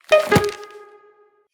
mailclose.ogg